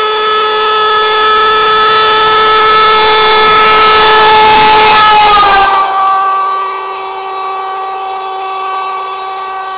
When the train is moving toward you, the wave crests are closer together and the whistle's pitch sounds higher. When the train is moving away from you, the wave crests are farther apart and the whistle's pitch sounds lower.
horn.wav